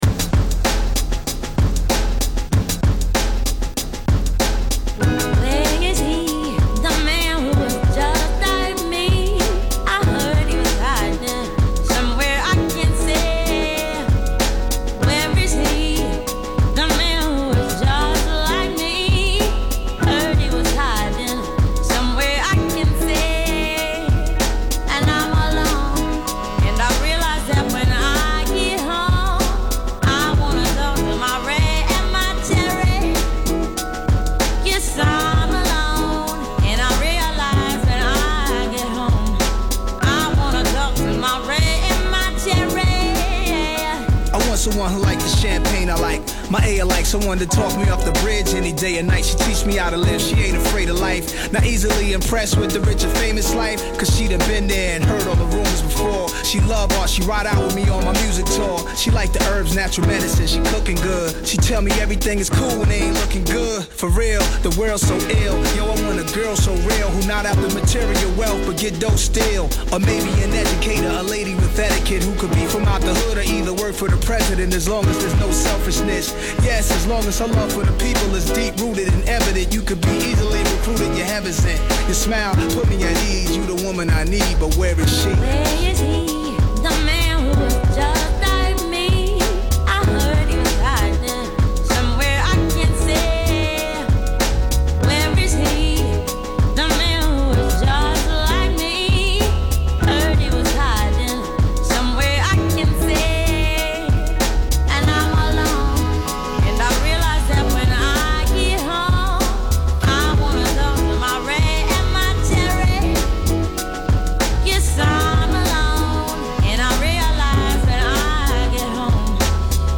robust vocals that carry the track
upbeat optimism